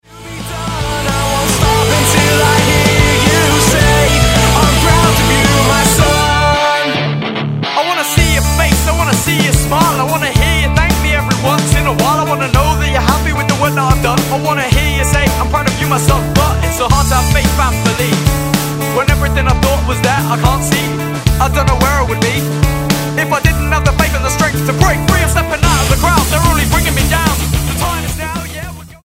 Rap rock
Style: Rock